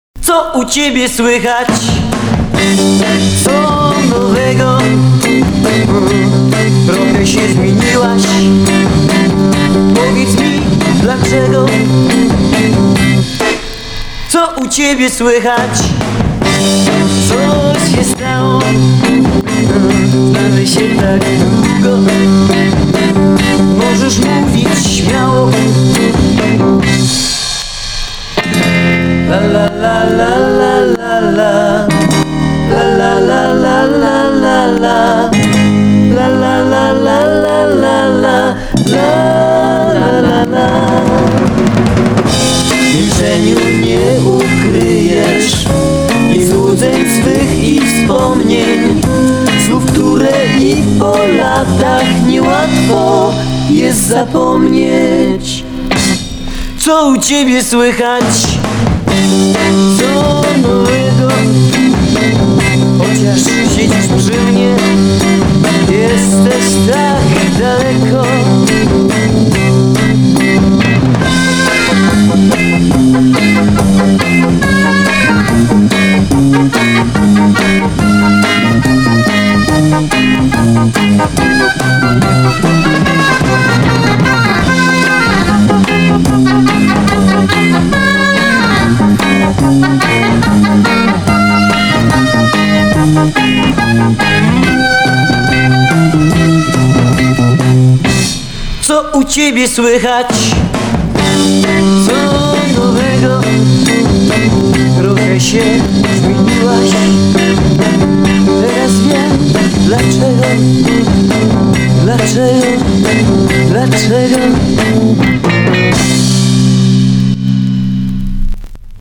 Пионеры польского биг-битa.